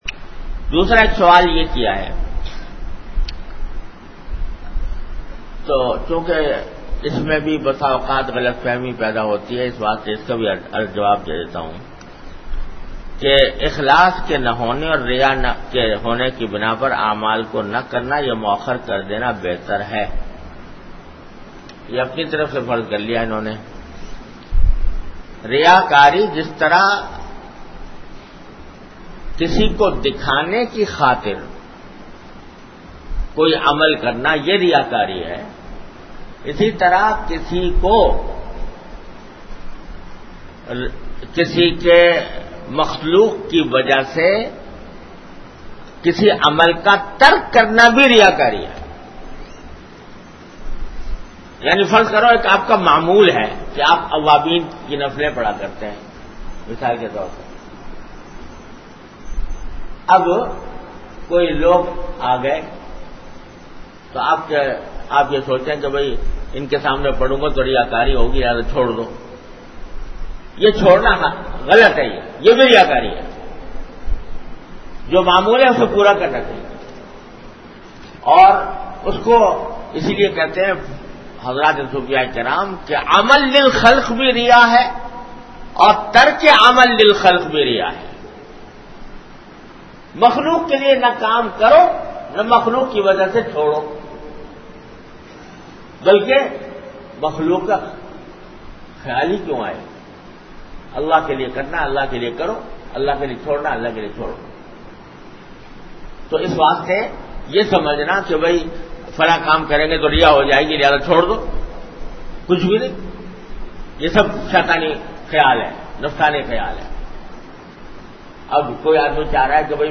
An Islamic audio bayan by Hazrat Mufti Muhammad Taqi Usmani Sahab (Db) on Bayanat. Delivered at Darululoom Karachi.